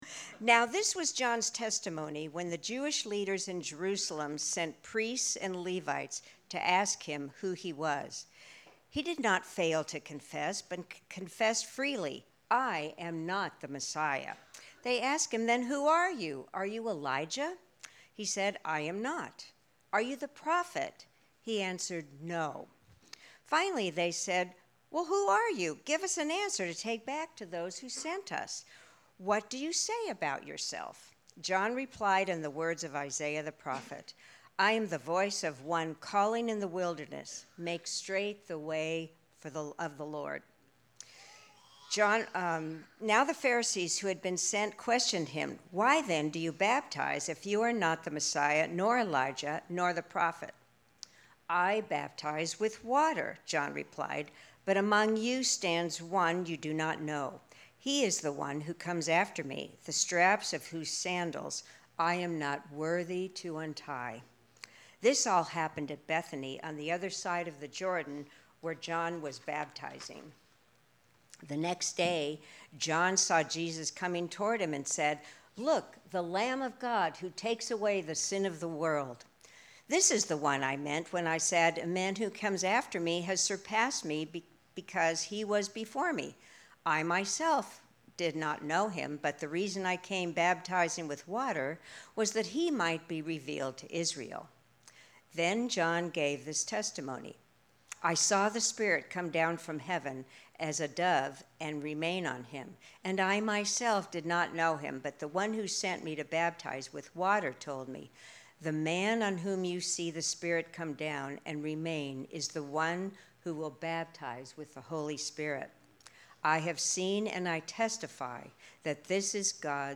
Sermon-1-6-26.mp3